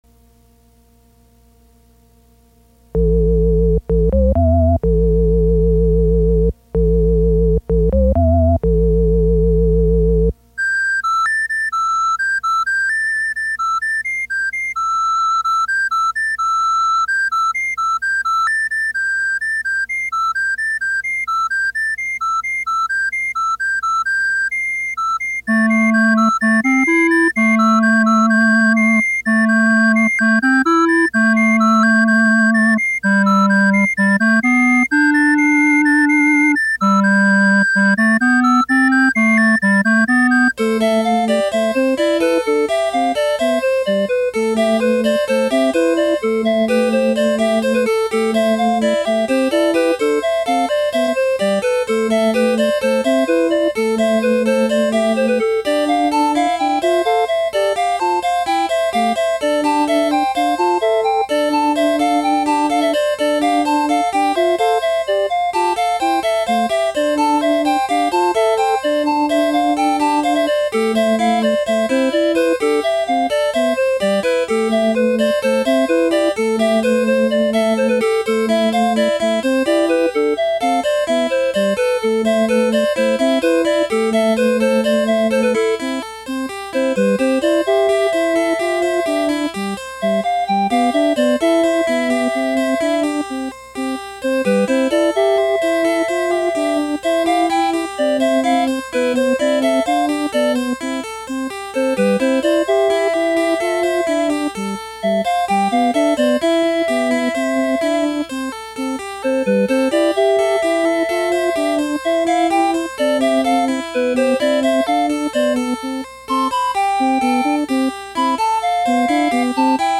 ＤＮＡの塩基配列をメロディとしているので、どうしても  音としてはランダムになってしまいます。そのランダムな音を、ベースとコードを入れることにより、上手く調和させています。
ＦＭ音源-エレクトリックオルガンのイントロ（７秒）が終わると、ＤＮＡ情報を変換したメロディを、クラリネットで１５秒間独奏 《がん遺伝子の哀愁、叫びのように聞こえます》　　引き続き、ベースが入って１５秒後にメロディ(ＤＮＡ情報の音符変換） の音源をクラリネットからハープシコード。ベースは、フルート。コードは、リコーダーに切り変えて演奏します。